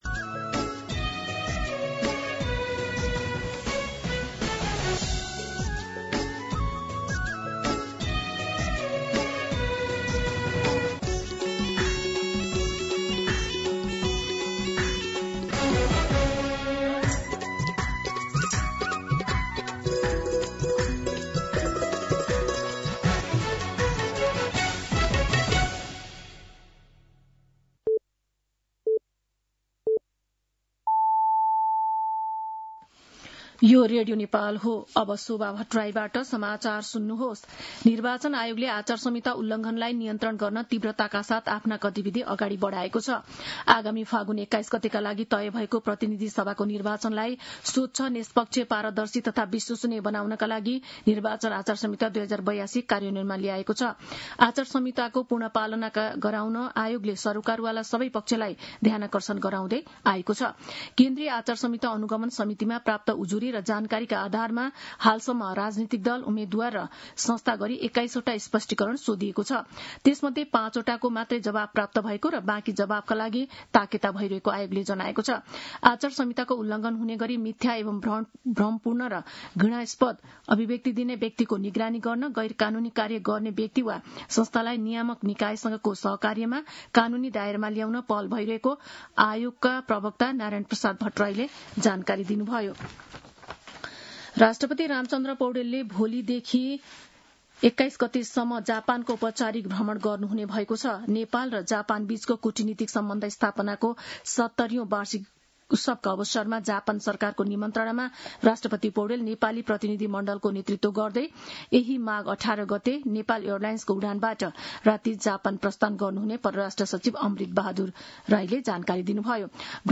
मध्यान्ह १२ बजेको नेपाली समाचार : १७ माघ , २०८२